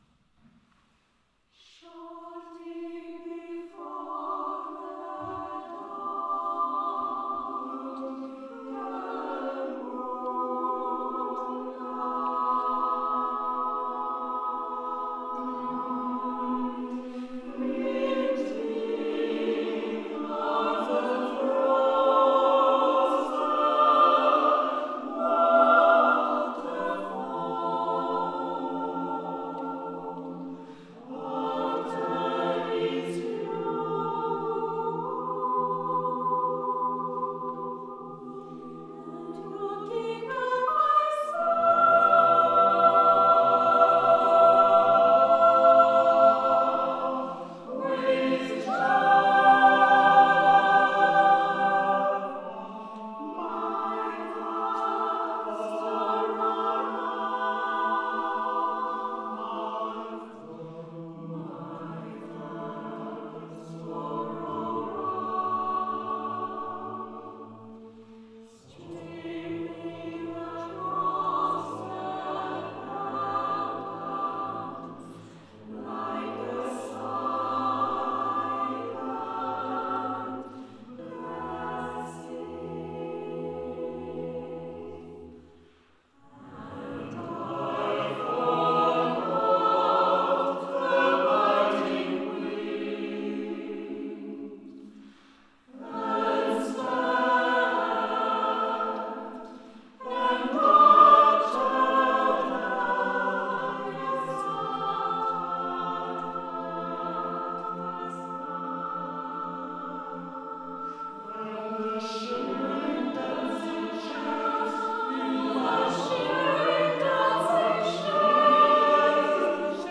Cette série de concerts a cappella mis en regard des pièces qui ont jalonné notre parcours musical avec des œuvres nouvelles, dont trois créations.
Quelques extraits de ces concerts :
Paroles et musique de Ian Assersohn.